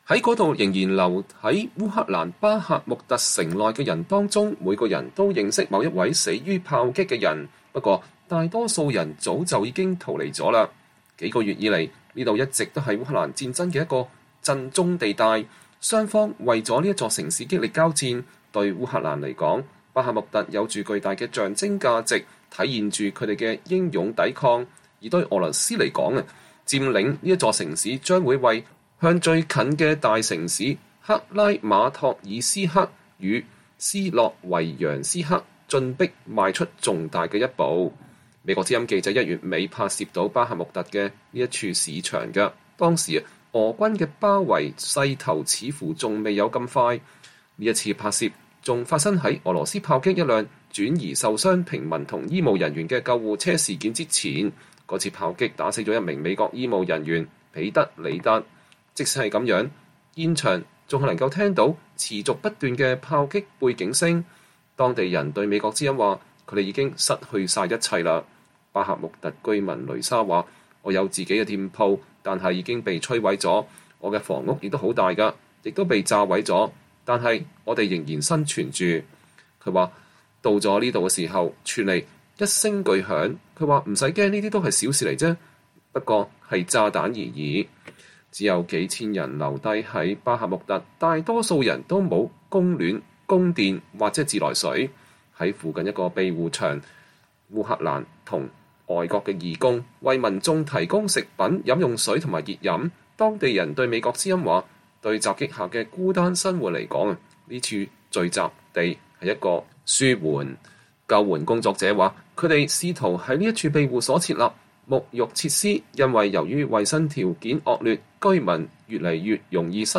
美國之音(VOA)記者1月末拍攝了巴赫穆特的這處市場。
即使如此，現場還是能聽到持續不斷的炮擊背景聲。